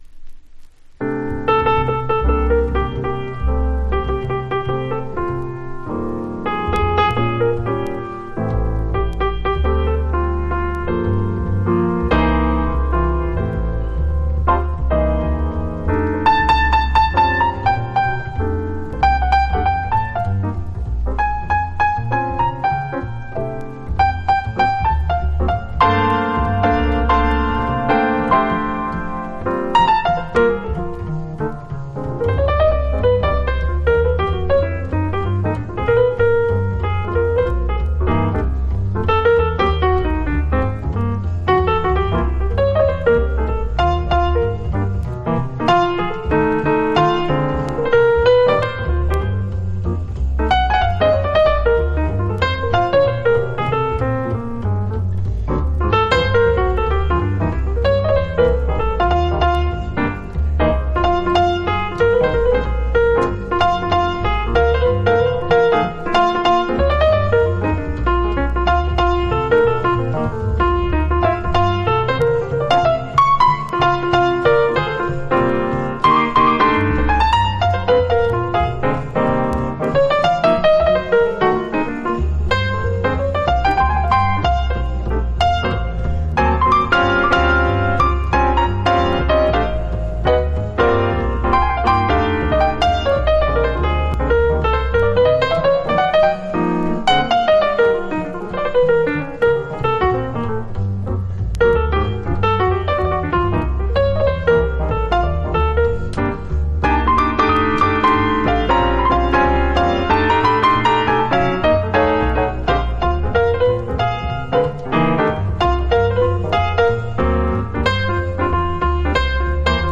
（小傷によりチリ、プチ音ある曲あり）※曲名をクリックすると試…